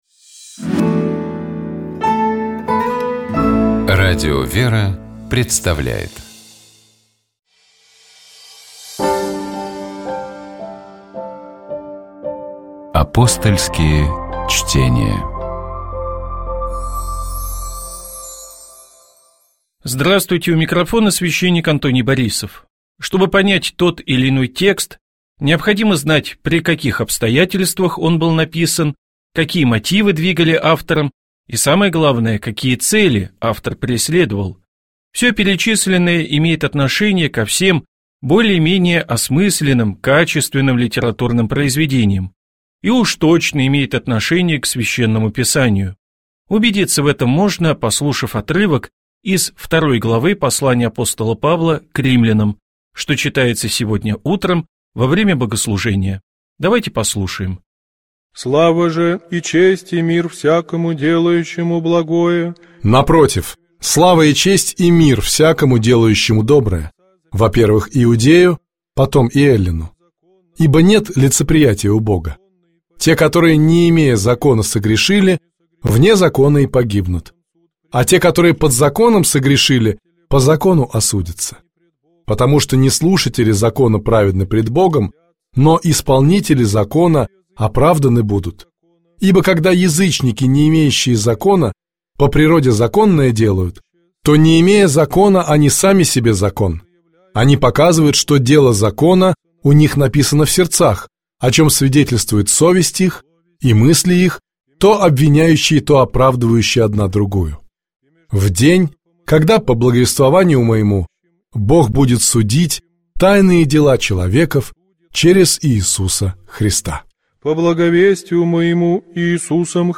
8 марта, в День памяти блаженной Матроны Московской, Святейший Патриарх Московский и всея Руси Кирилл совершил Божественную Литургию в Покровском монастыре города Москвы. На проповеди Предстоятель Русской Православной Церкви говорил о силе Божьей, совершающейся в немощи.